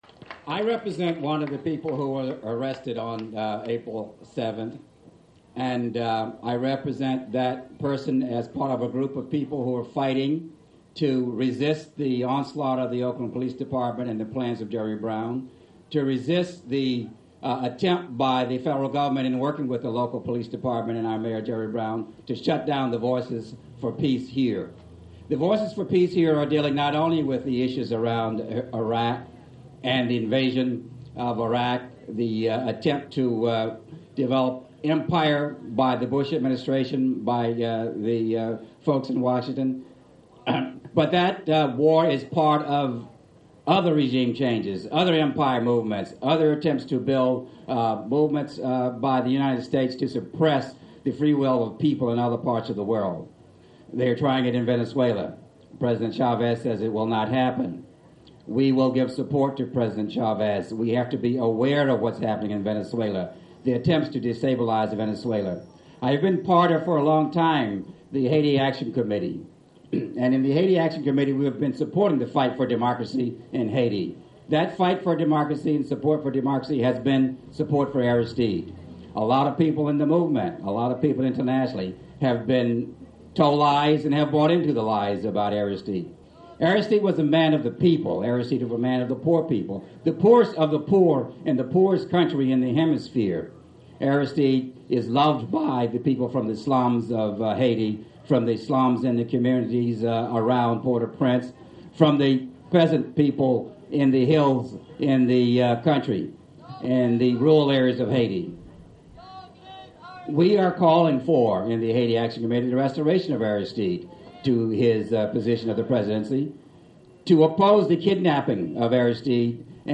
April 7, 2004: One year after Oakland Police attacked the first such direct action against war profiteers APL and SSA, antiwar protesters once again shutdown the SSA docks at the Port of Oakland... here are some audio clips.